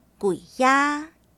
guiˊ ia
guiˇ ia